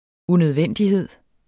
Udtale [ unøðˈvεnˀdiˌheðˀ ]